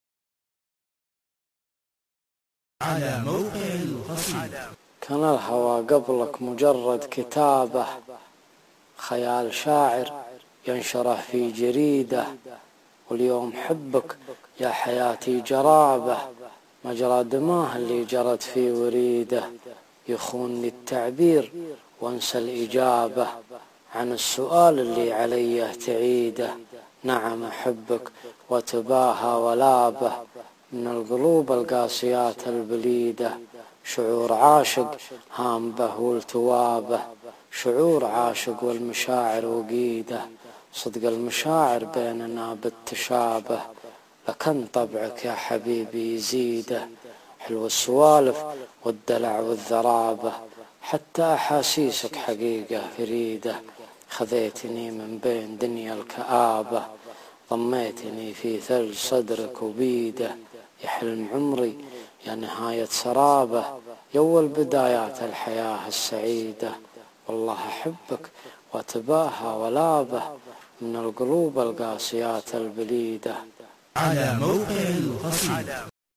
kan_alhwa_bdwn_mwsyka.mp3